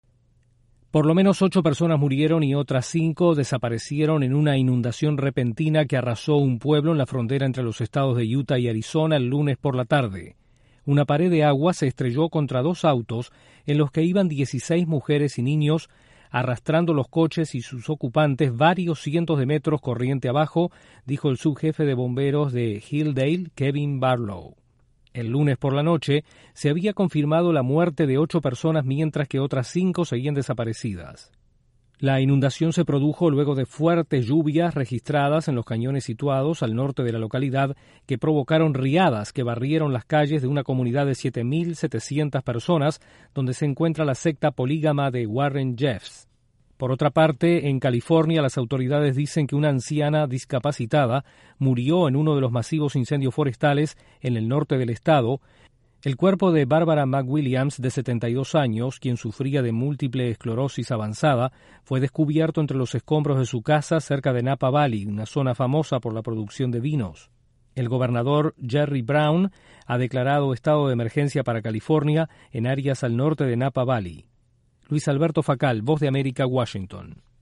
Ocho muertos por inundaciones repentinas en EE.UU., mientras incendios forestales causan un muerto. Desde la Voz de América en Washington informa